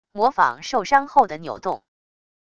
模仿受伤后的扭动wav音频